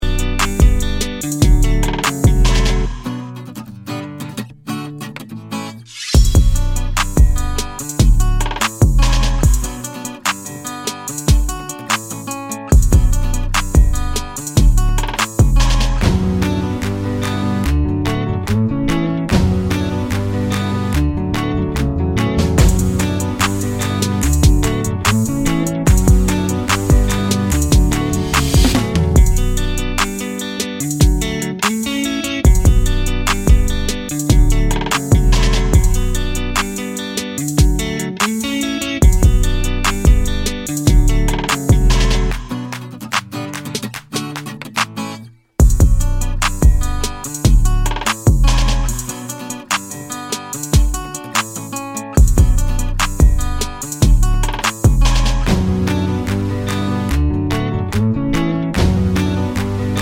no Backing Vocals R'n'B / Hip Hop 2:41 Buy £1.50